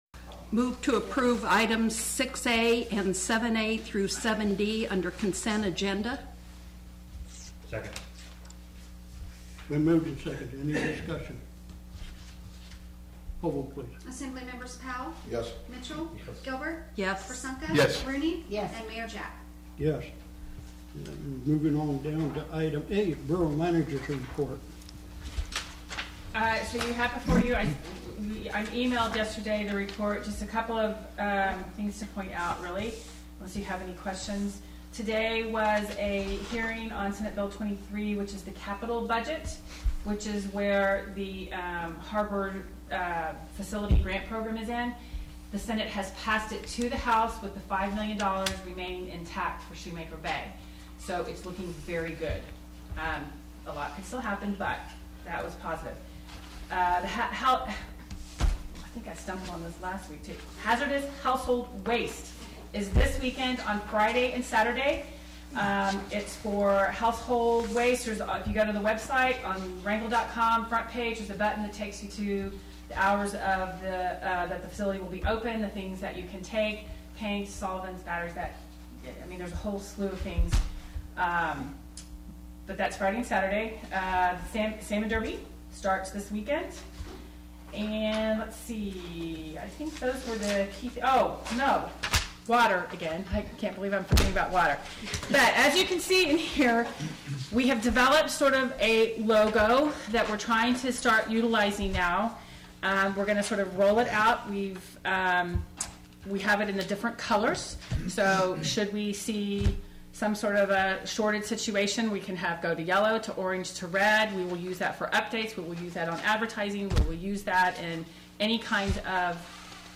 The Wrangell Borough Assembly met for a regular meeting on Tuesday, May 9th in Wrangell Assembly Chambers.
May 9, 2017 7:00 p.m. Location: Assembly Chambers, City Hall